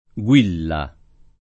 vai all'elenco alfabetico delle voci ingrandisci il carattere 100% rimpicciolisci il carattere stampa invia tramite posta elettronica codividi su Facebook Guilla [ gU& lla ] (meno com. Ghilla [ g& lla ]) pers. f. stor.